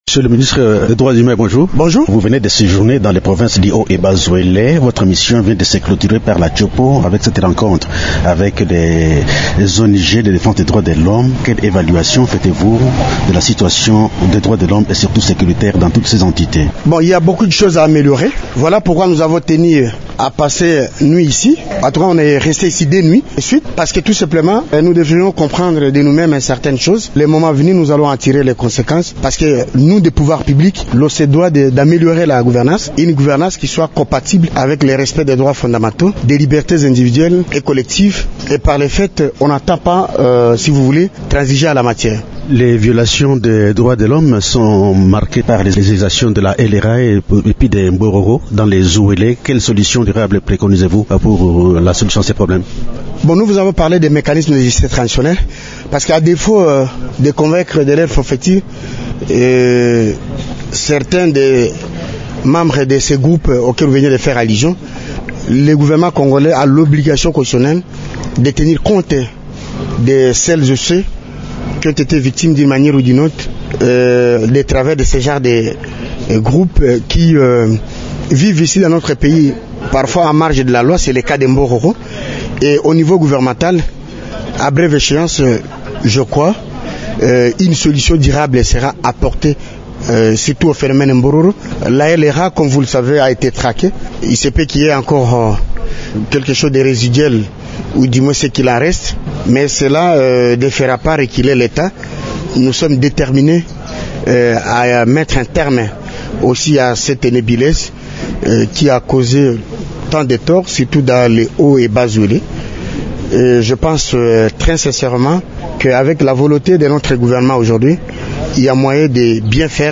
Dans  cette interview que vous pouvez écouter ici, le ministre des droits humains s’exprime aussi sur la prise en charge des détenus dans les prisons ainsi que la question de l'indemnisation des victimes des abus et violations des droits de l’homme.